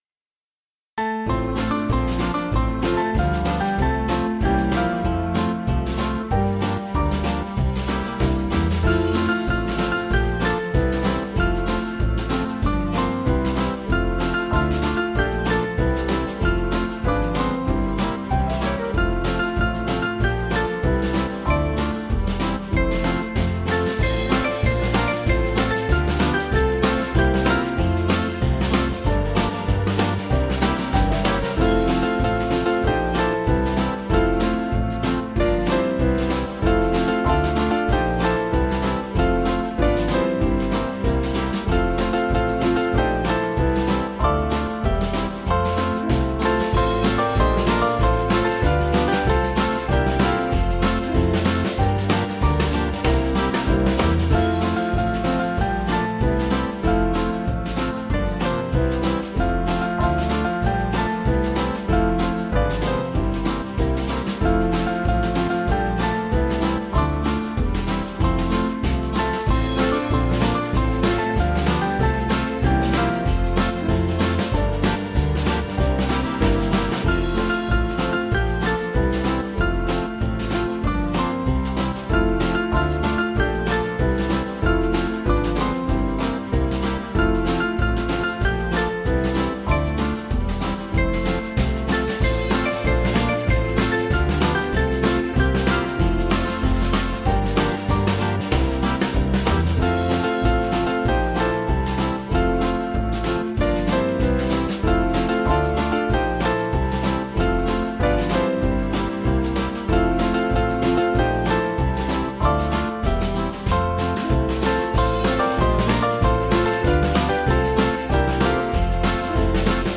West Indian Spiritual
Small Band